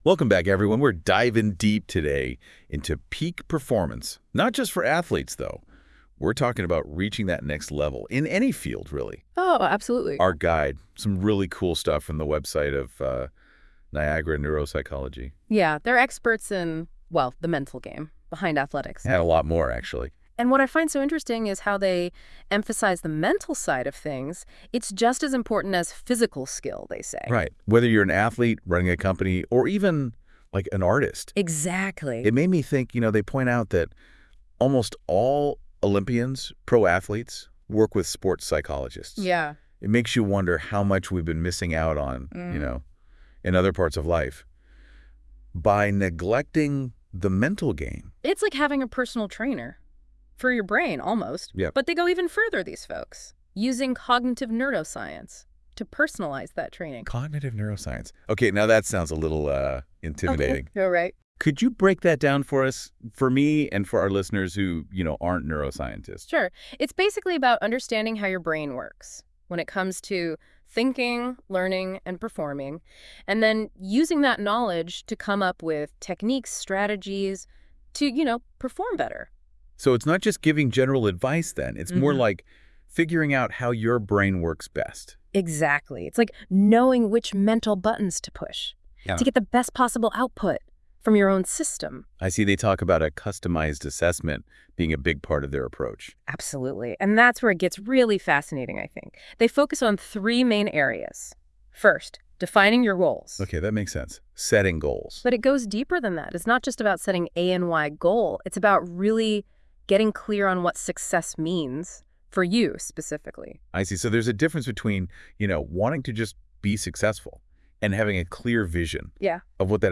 CLICK TO HEAR AN AI GENERATED DISCUSSION OF THIS PAGE: